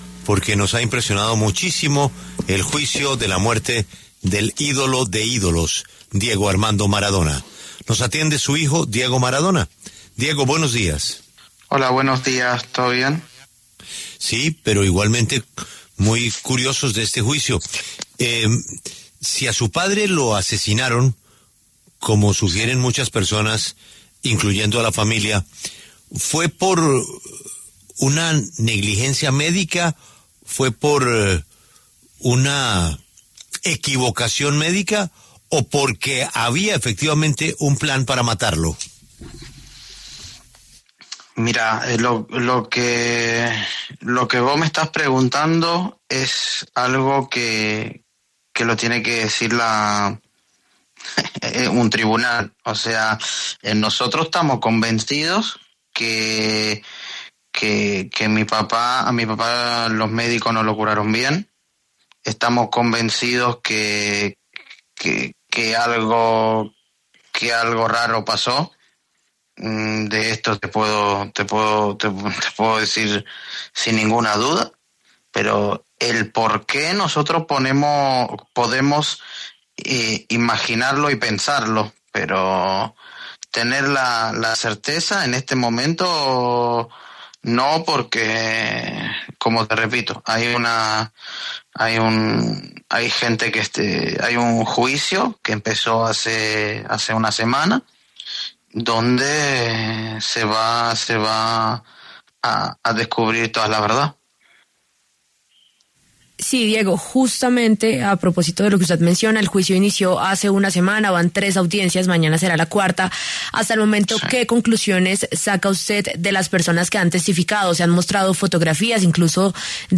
El hijo del astro argentino, Diego Armando Maradona, habló en La W a propósito del juicio que se desarrolla por la muerte del futbolista en Argentina.